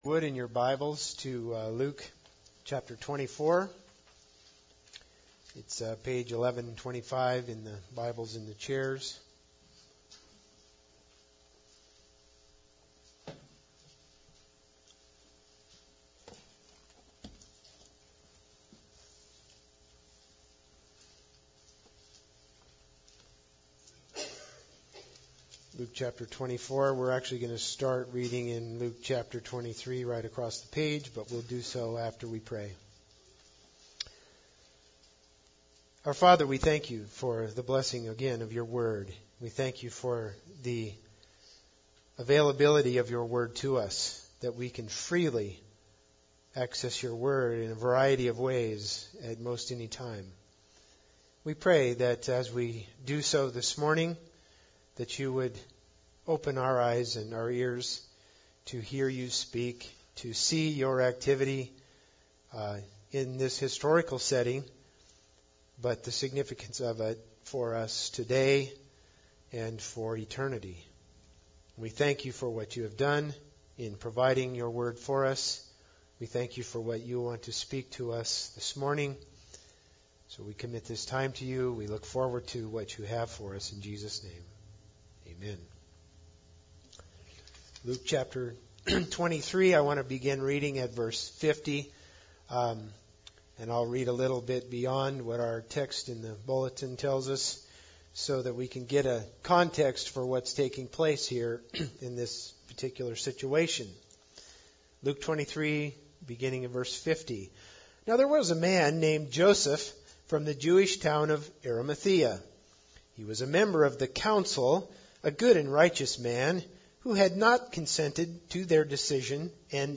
Luke 24:13-25 Service Type: Sunday Service Bible Text